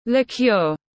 Rượu mùi tiếng anh gọi là liqueur, phiên âm tiếng anh đọc là /lɪˈkjʊər/
Liqueur /lɪˈkjʊər/